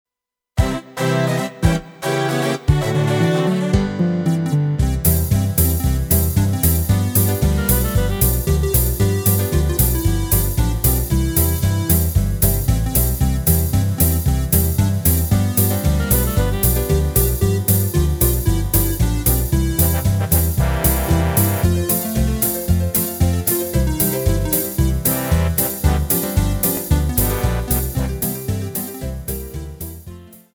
Rubrika: Pop, rock, beat
HUDEBNÍ PODKLADY V AUDIO A VIDEO SOUBORECH